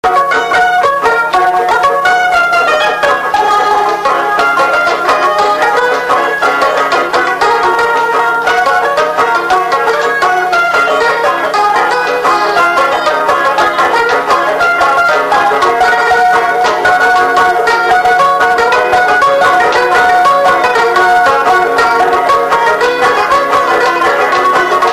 Séga
Instrumental
danse : séga
Pièce musicale inédite